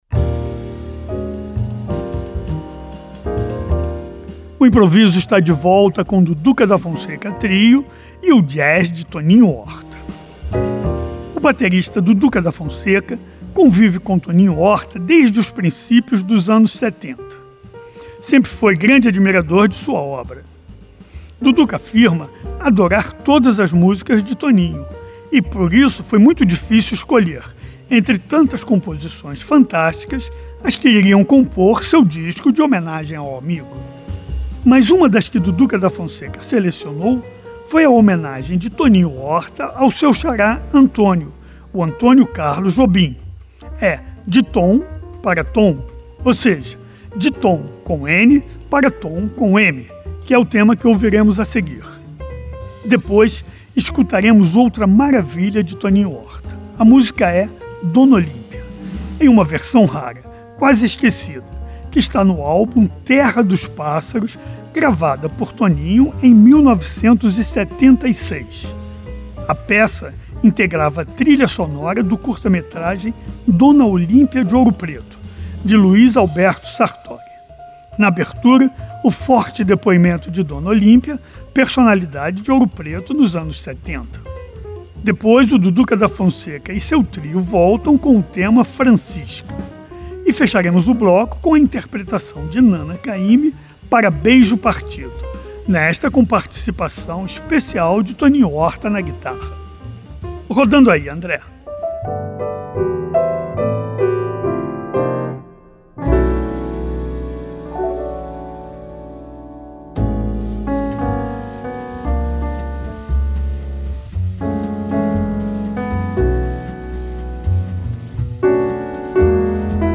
O baterista brasileiro